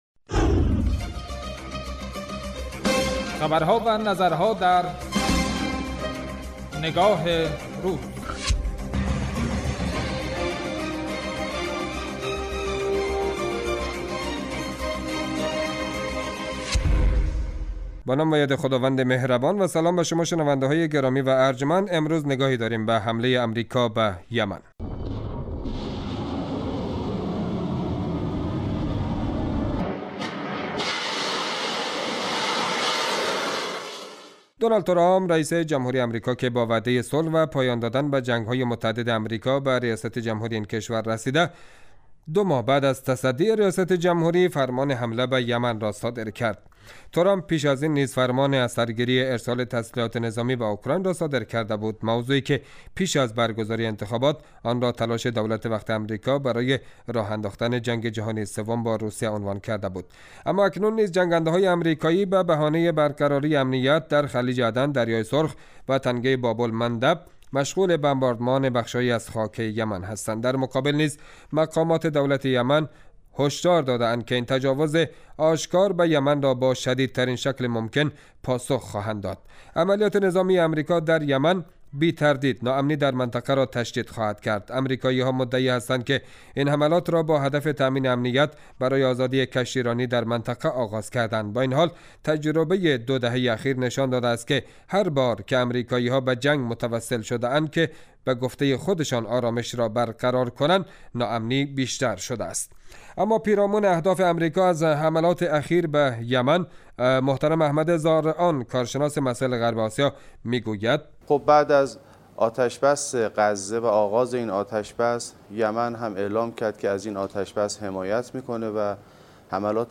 اطلاع رسانی و تحلیل و تبیین رویدادها و مناسبتهای مهم ، رویکرد اصلی برنامه نگاه روز است .